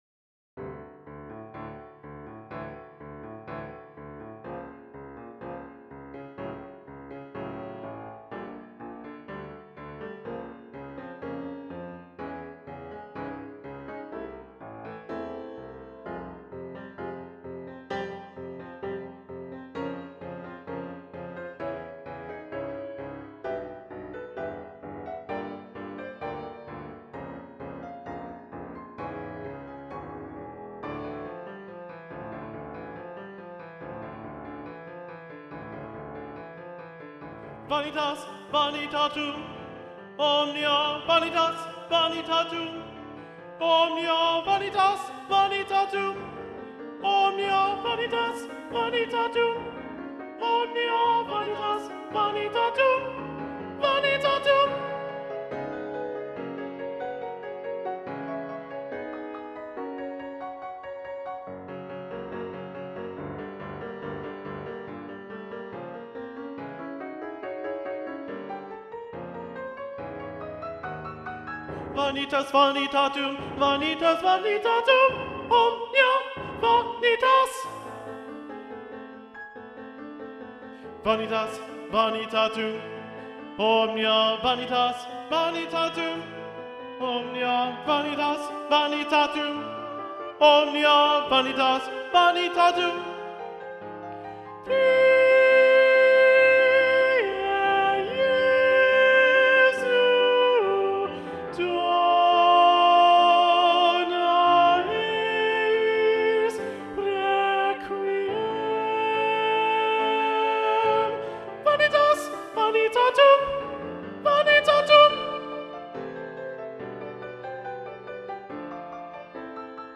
Video Only: Vanitas Vanitatum - Alto 1 Predominant